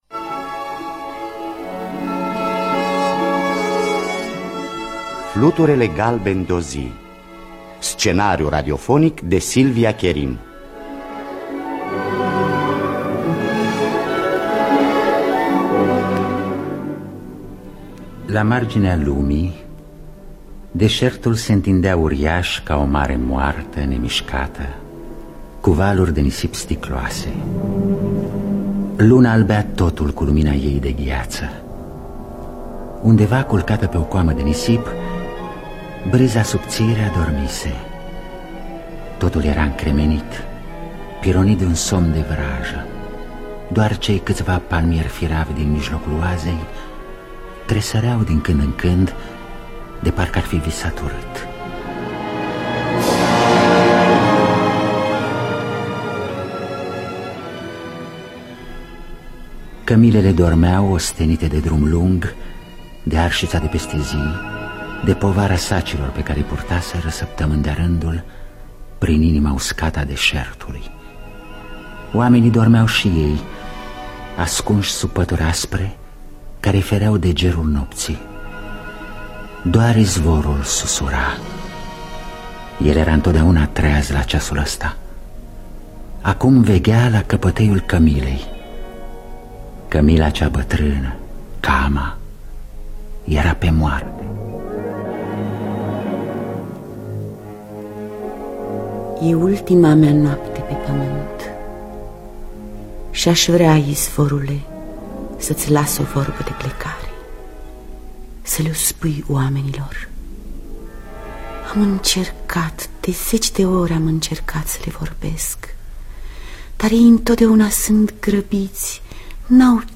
Silvia Kerim – Fluturele Galben De O Zi (1979) – Teatru Radiofonic Online